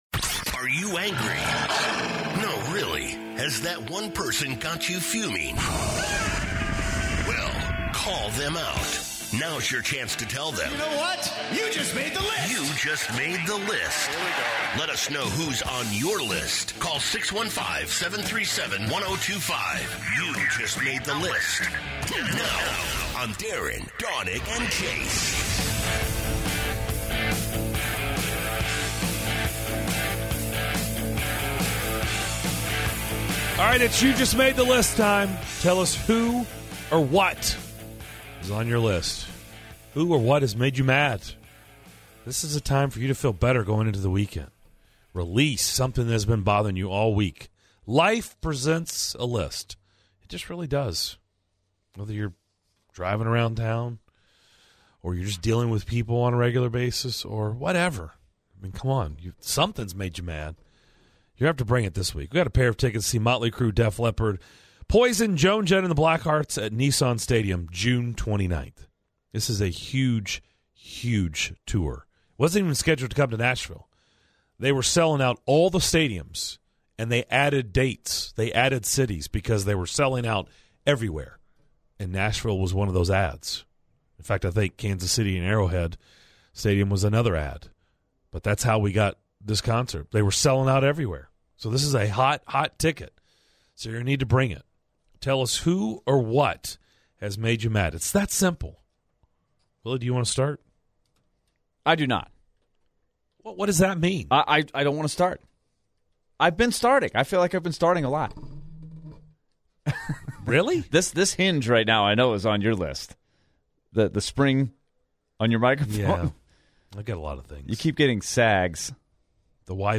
In the third hour of the show, the guys take calls for You Just Made The List and discuss the Preds' win over the Chicago Blackhawks.